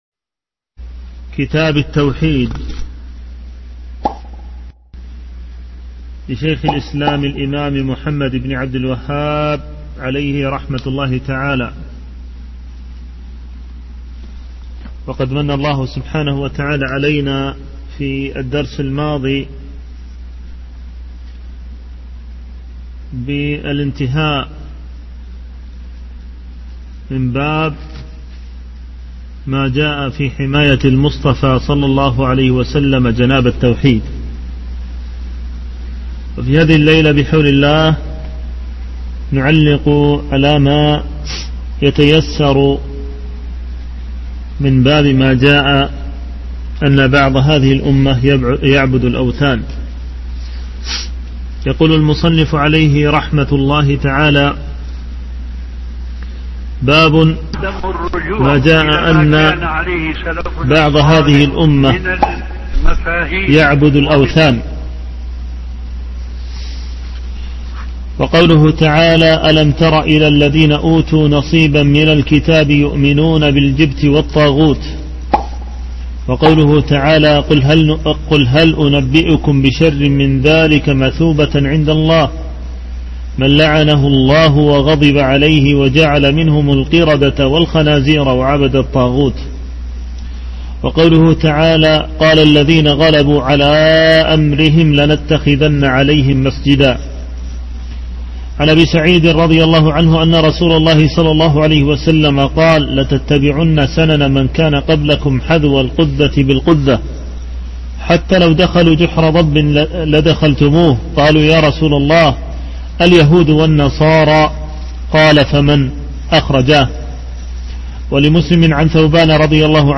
شرح كتاب التوحيد الذي هو حق الله على العبيد الدرس 21